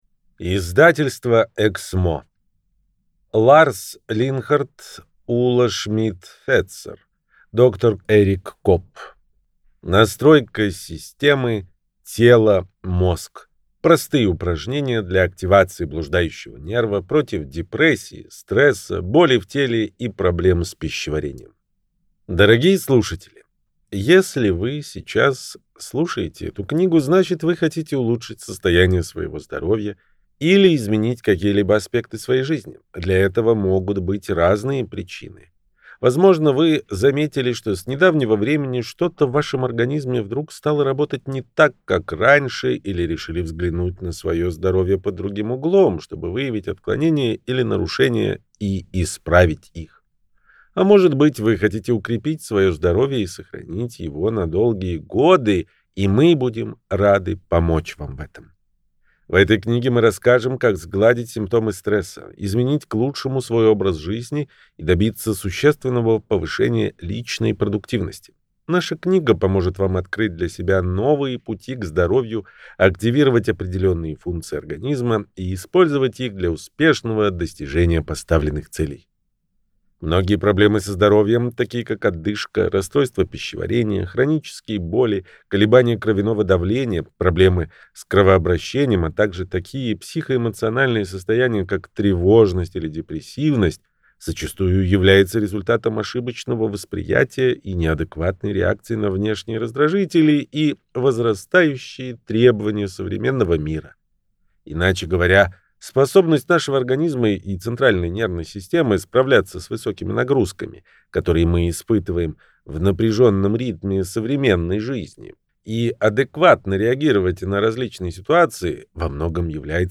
Аудиокнига Настройка системы тело – мозг. Простые упражнения для активации блуждающего нерва против депрессии, стресса, боли в теле и проблем с пищеварением | Библиотека аудиокниг